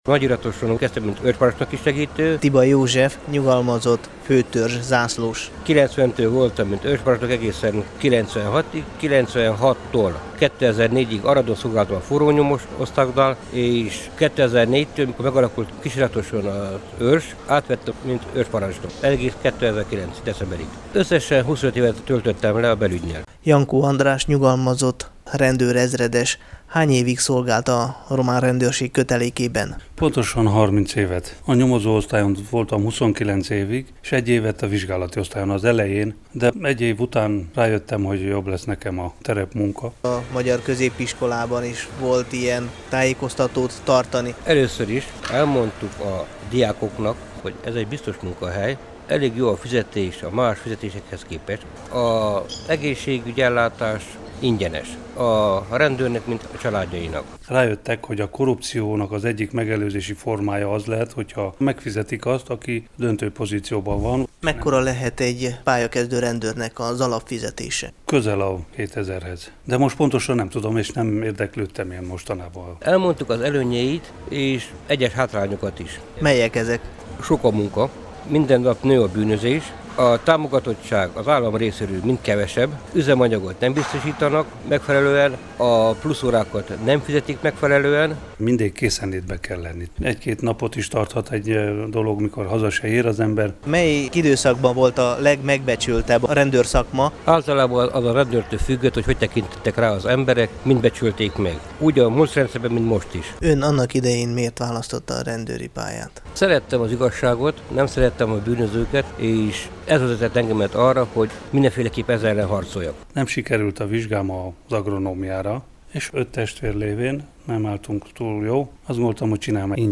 Romániában magyar rendőrként – a rádió archívumából [AUDIÓ]